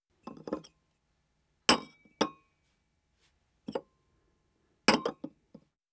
dining.wav